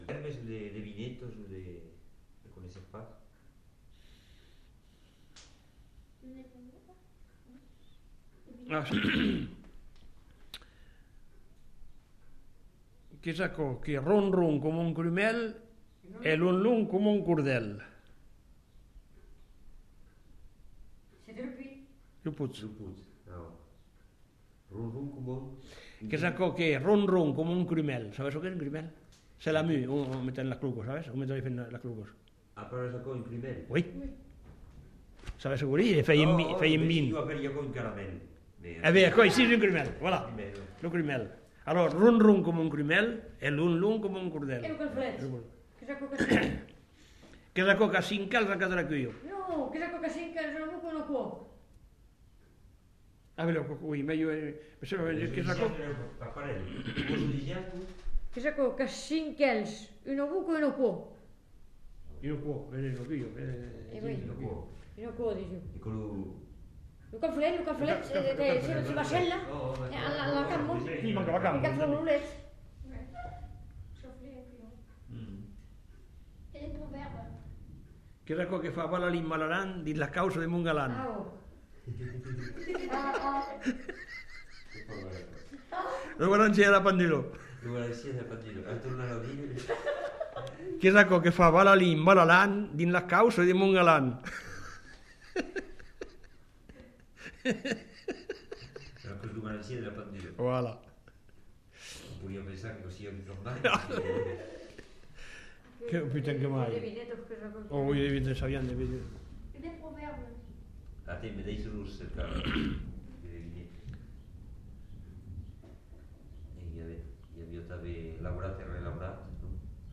Aire culturelle : Quercy
Lieu : Valprionde
Genre : forme brève
Type de voix : voix d'homme ; voix de femme
Production du son : parlé
Classification : devinette-énigme